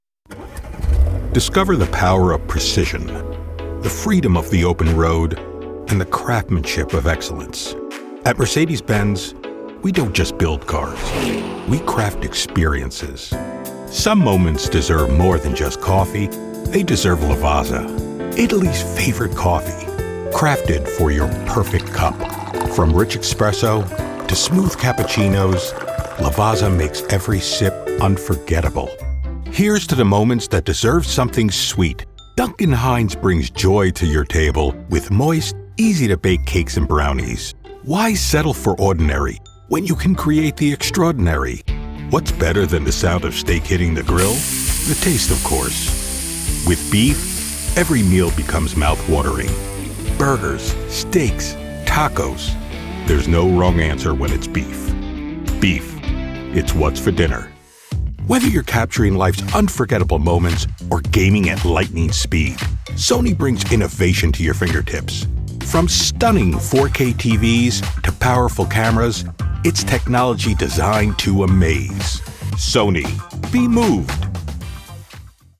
Warm, confident, and engaging.
Full Commercial Demo – Confident, Clear, and Dynamic Delivery
Middle Aged
I record from a professional home studio with broadcast-quality sound and offer quick turnaround times.